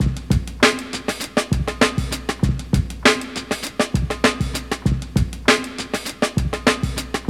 JB LOOP 1.wav